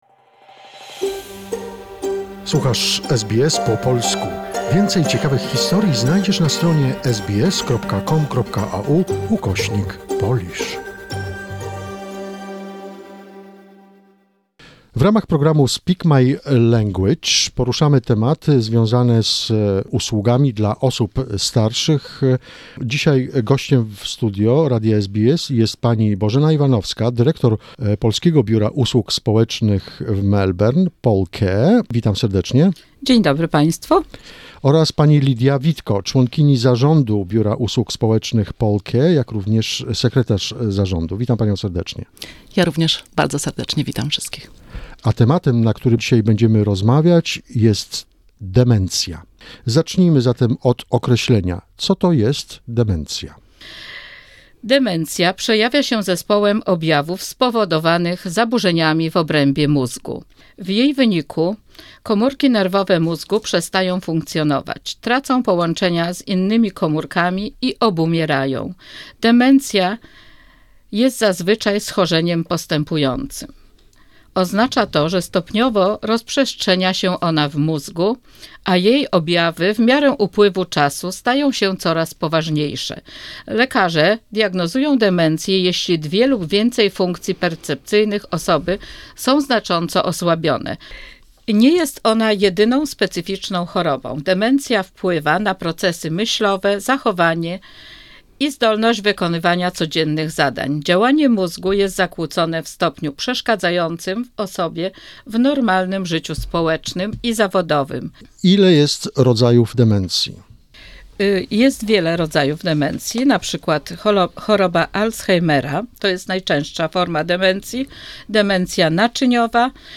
This is another conversation in the "Speak My Language" series about helping the elderly in Australia.